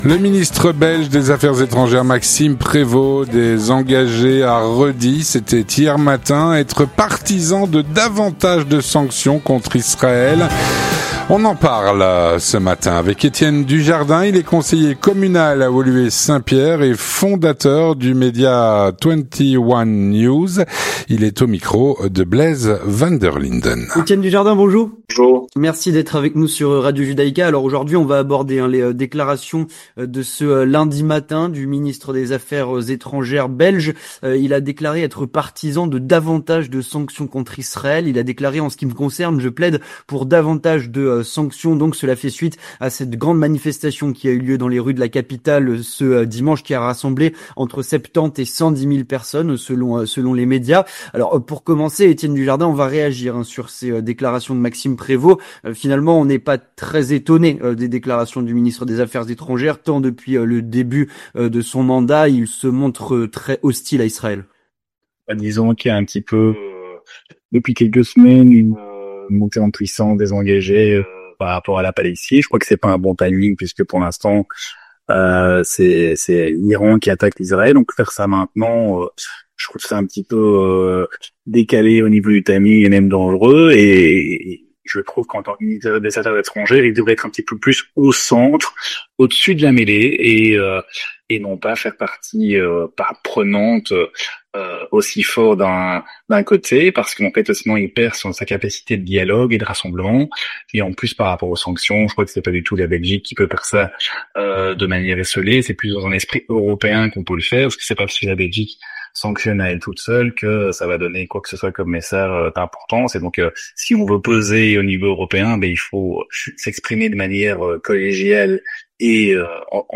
On en parle avec Etienne Dujardin, conseiller communal à Woluwe Saint-Pierre et fondateur du média 21 news.